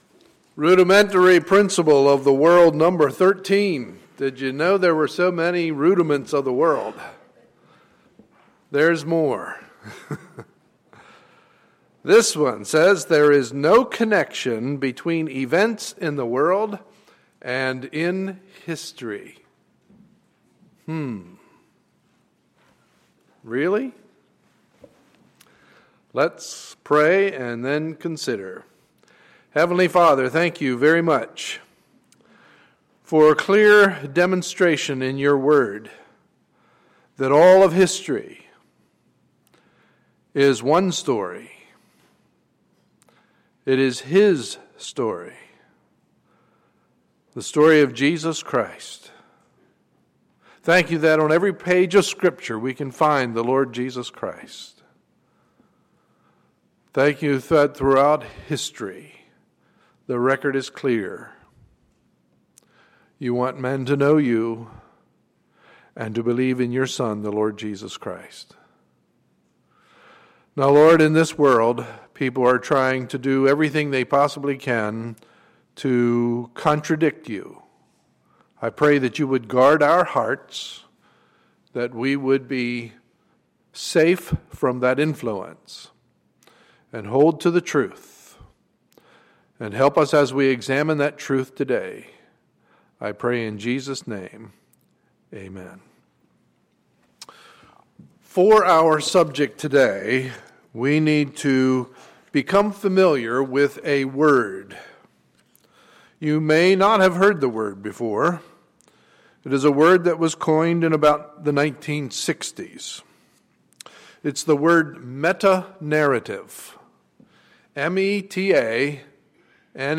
Sunday, June 8, 2014 – Morning Service